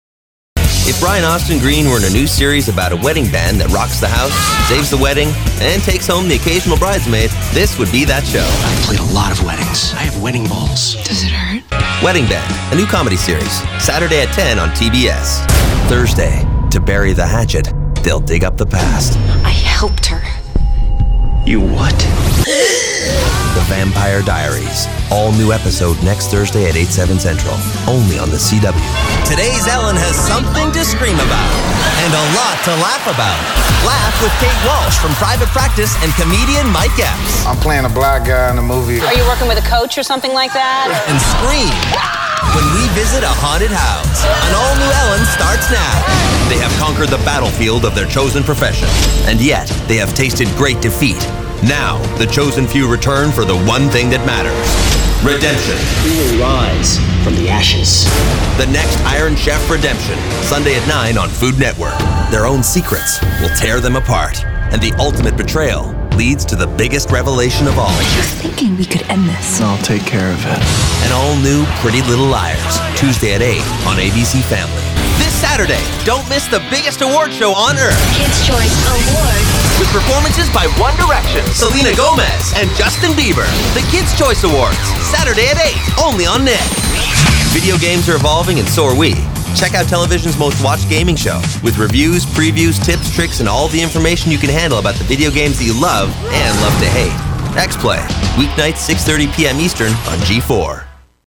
authentic, honest, believable Midwestern voice, All-American Approachable Articulate Attractive Authoritative Believable Casual Charming Classy Clear Comedic Comforting Confident Conversational Cool Corporate Crisp Direct Down to earth Dynamic Educated Energetic Engaging Friendly Funny Generation X Generation Y Genuine Guy Next Door Happy Honest Informative Intelligent Mature Midwest Midwestern Modern National Natural Neutral Refreshing Sincere Smart Smooth Trustworthy Upbeat Versatile Youthful.
Sprechprobe: Sonstiges (Muttersprache):